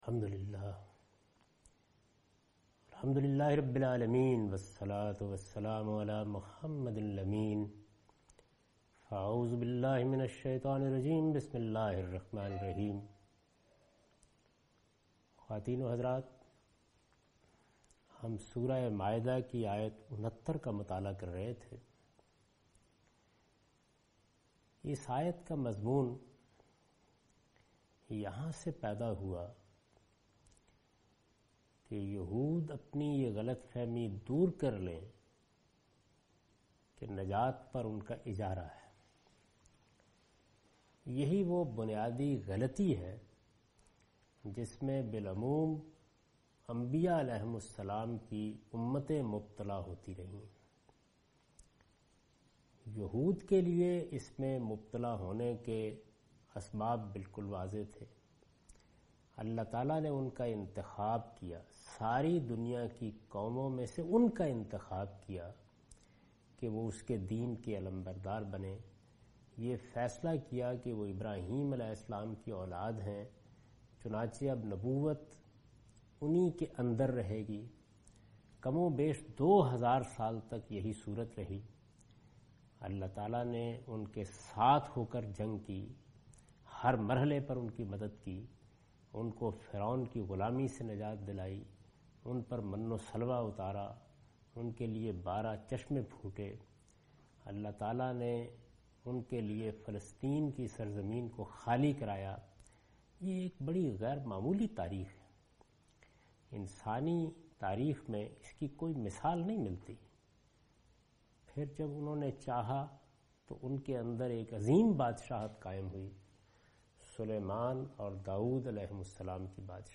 Surah Al-Maidah - A lecture of Tafseer-ul-Quran – Al-Bayan by Javed Ahmad Ghamidi. Commentary and explanation of verse 69 and 71